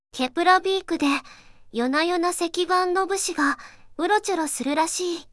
voicevox-voice-corpus / ROHAN-corpus /四国めたん_セクシー /ROHAN4600_0035.wav